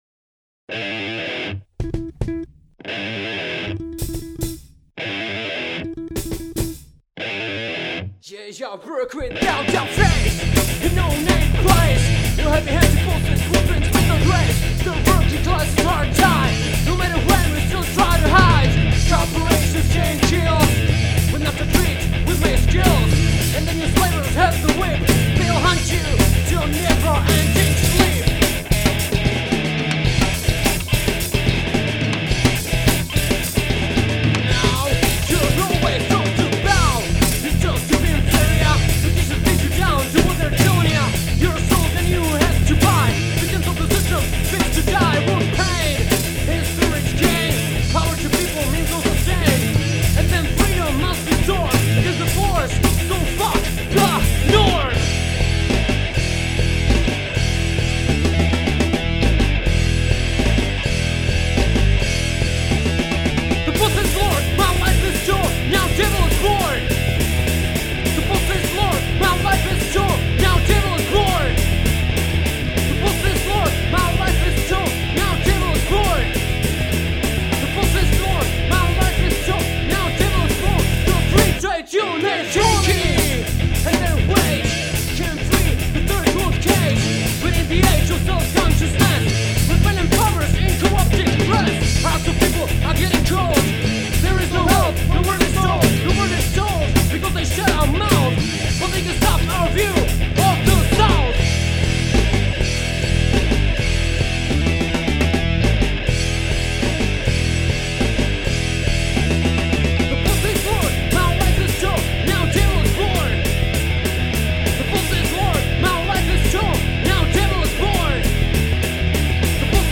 Kapela na pomezí HC a hip-hopu
zpěv
kytary
bicí